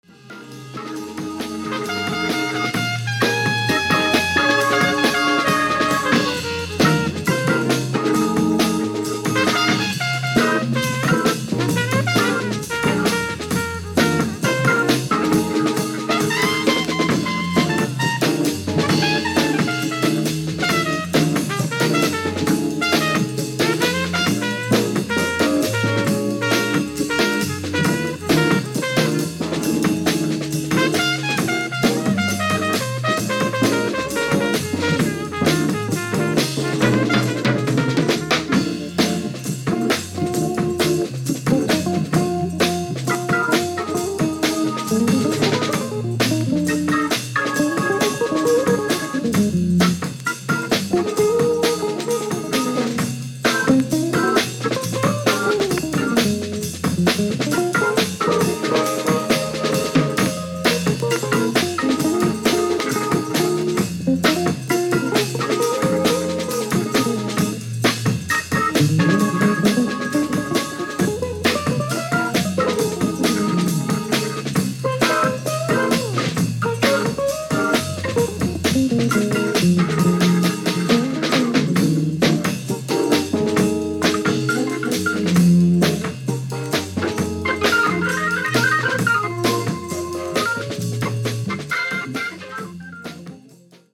Baritone Saxophone
Bass
Drums, Gong
Piano
Tenor Saxophone